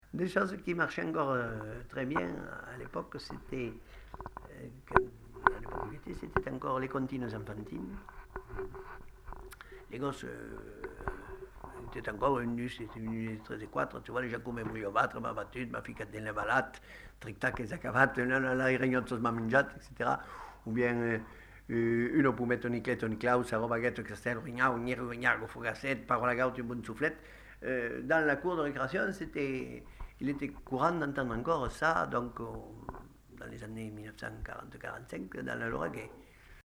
Aire culturelle : Lauragais
Genre : forme brève
Effectif : 1
Type de voix : voix d'homme
Production du son : récité
Classification : comptine